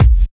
WU_BD_067.wav